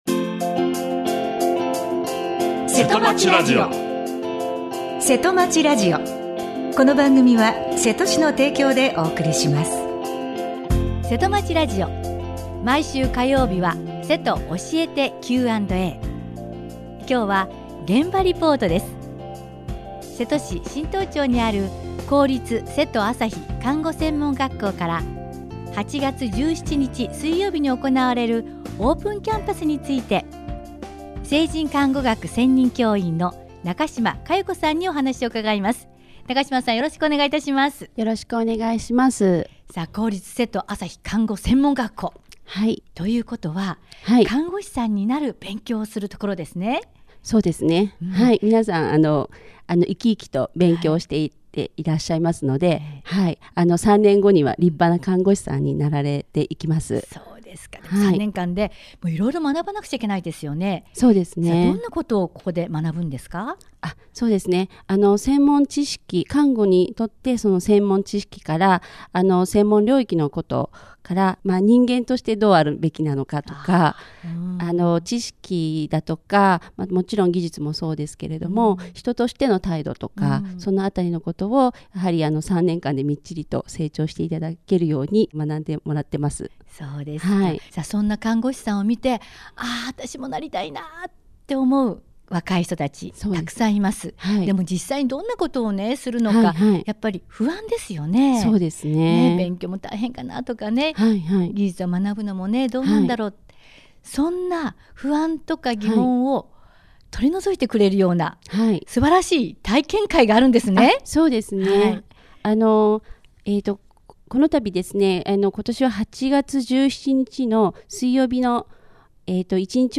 今日は、現場リポートです。